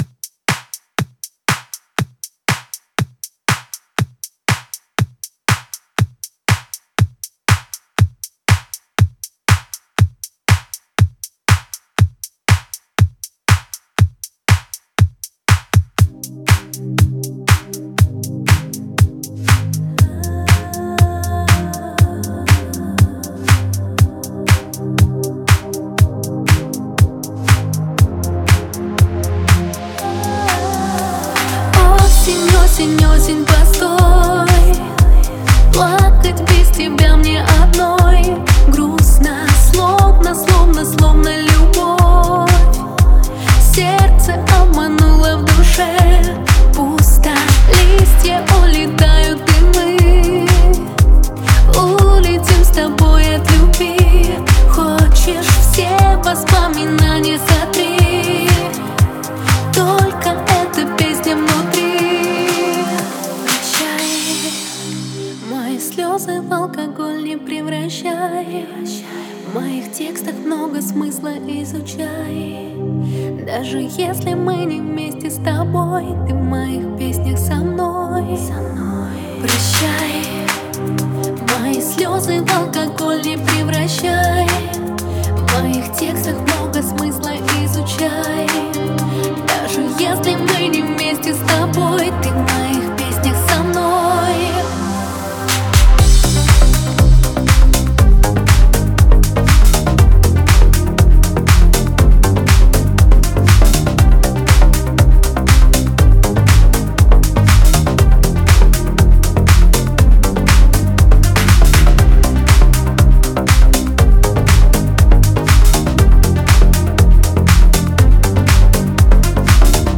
это трек в жанре поп-электроники